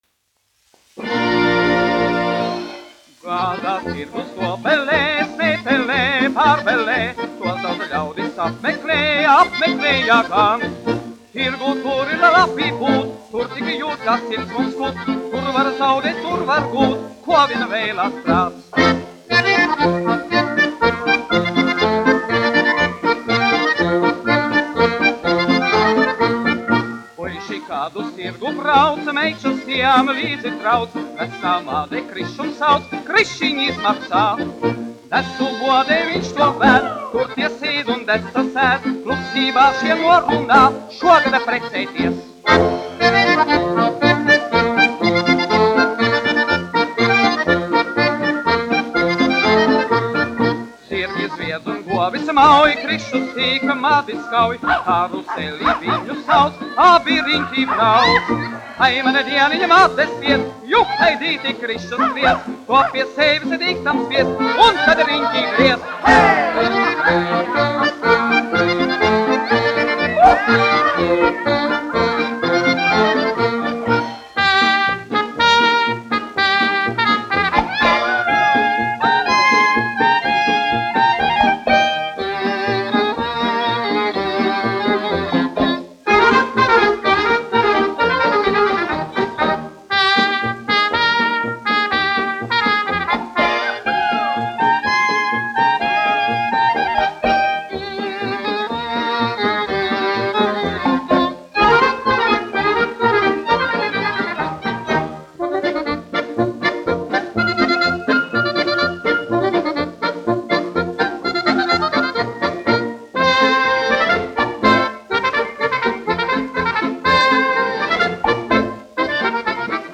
1 skpl. : analogs, 78 apgr/min, mono ; 25 cm
Polkas
Populārā mūzika -- Latvija
Skaņuplate